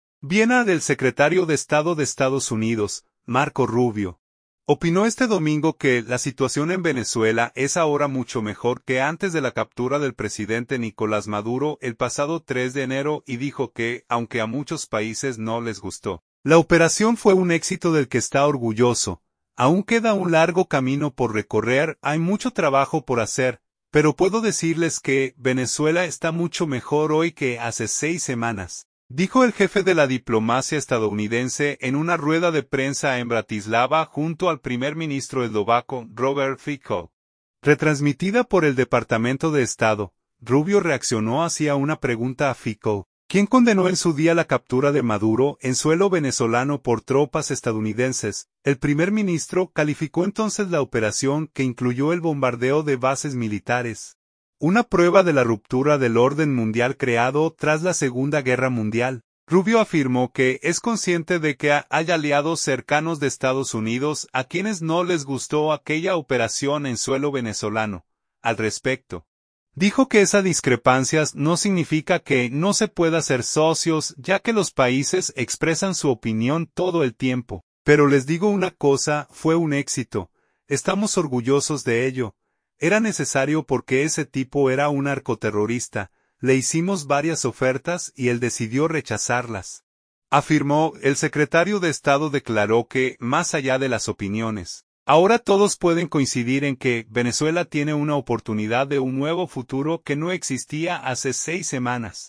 "Aún queda un largo camino por recorrer, hay mucho trabajo por hacer. Pero puedo decirles que Venezuela está mucho mejor hoy que hace seis semanas", dijo el jefe de la diplomacia estadounidense en una rueda de prensa en Bratislava junto al primer ministro eslovaco, Robert Fico, retransmitida por el Departamento de Estado.